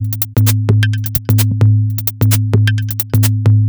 Salamanderbrain3 130bpm.wav